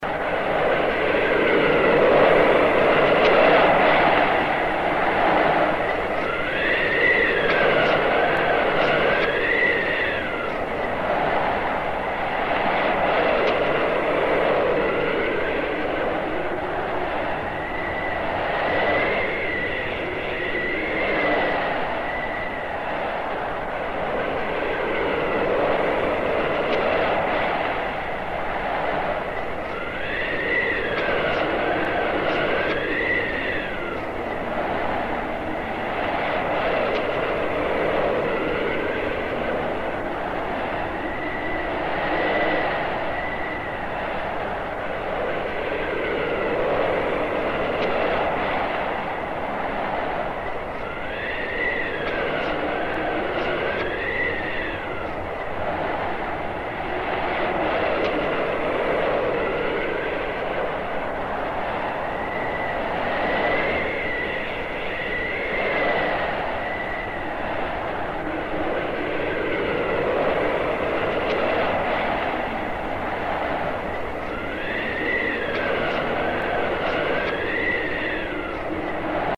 Звуки вьюги, метели
Шум зимней вьюги и метели